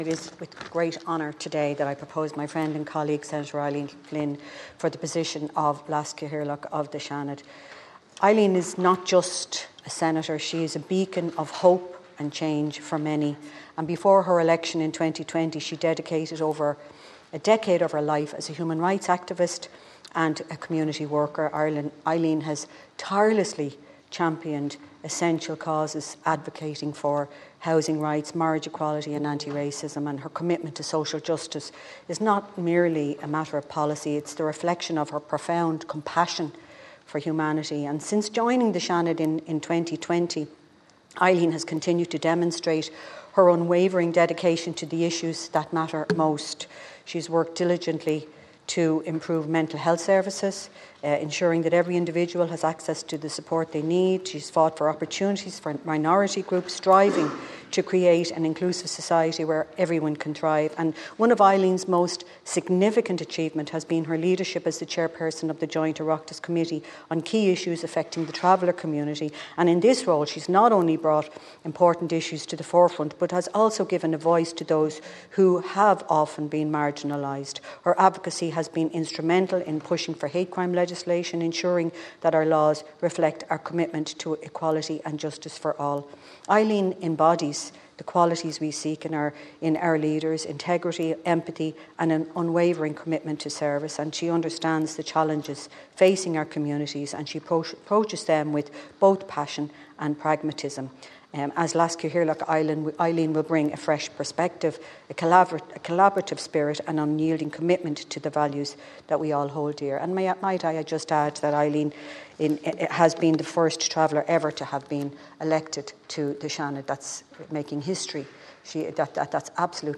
In proposing her Seanad colleague as Leas Cathaoirleach earlier, Senator Frances Black spoke of Senator Flynn’s dedication to humanitarian issues: